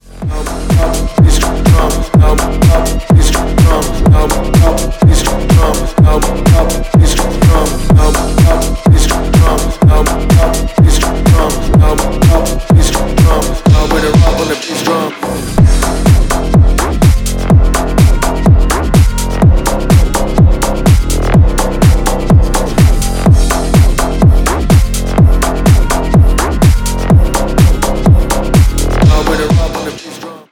клубные , g-house , басы